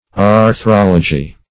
Meaning of arthrology. arthrology synonyms, pronunciation, spelling and more from Free Dictionary.
Search Result for " arthrology" : The Collaborative International Dictionary of English v.0.48: Arthrology \Ar*throl"o*gy\, n. [Gr.